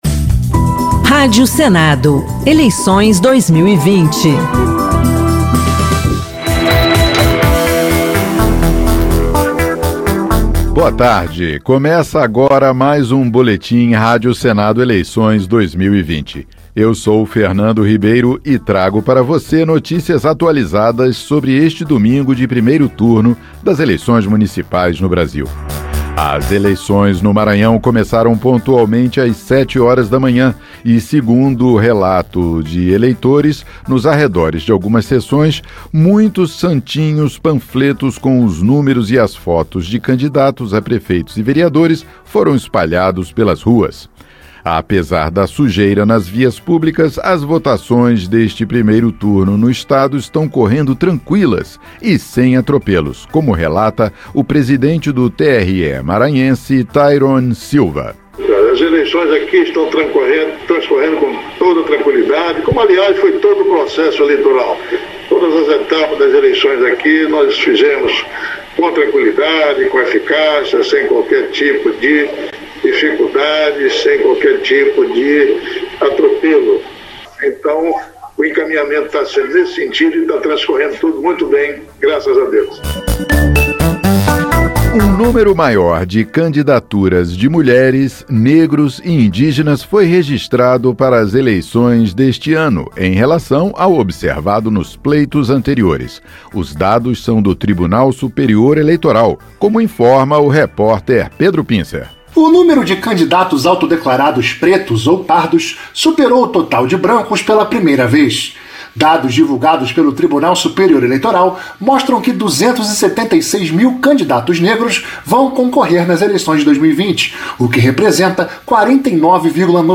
Cobertura especial